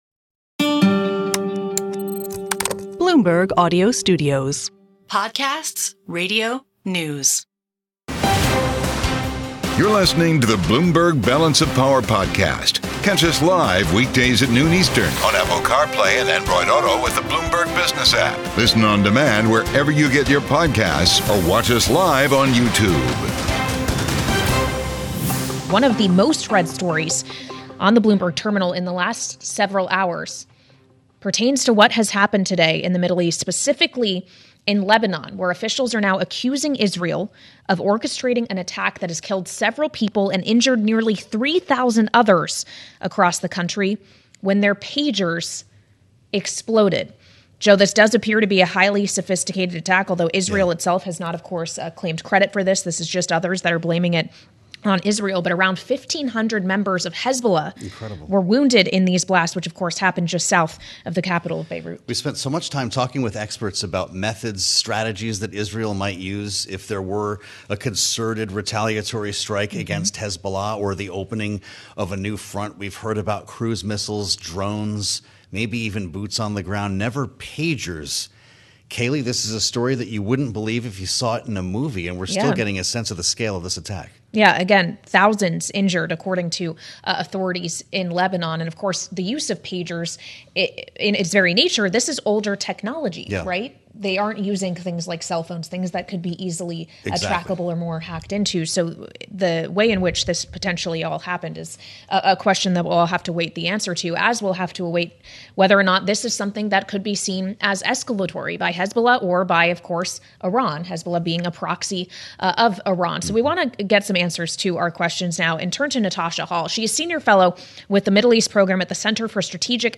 Politics